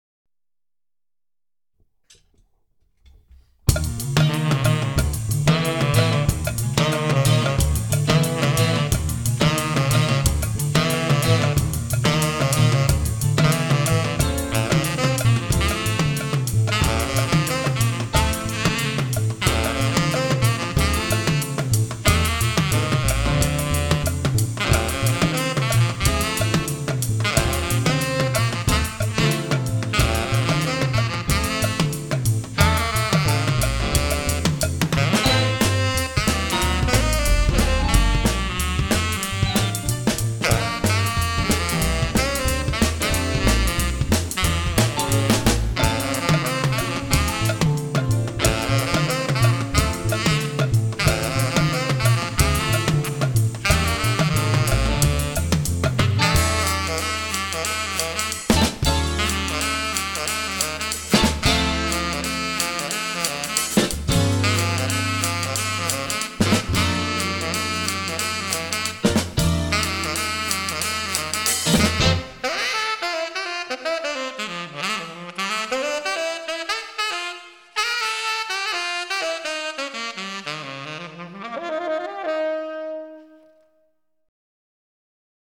SWING / BEBOP